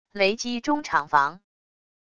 雷击中厂房wav音频